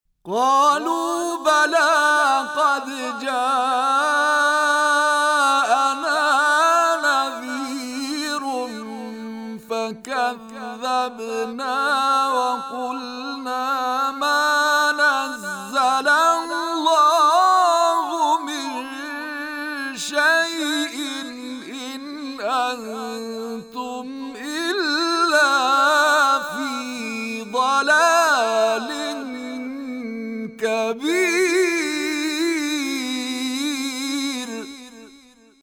گروه شبکه اجتماعی: نغمات صوتی از تلاوت‌های قاریان به‌نام کشور را می‌شنوید.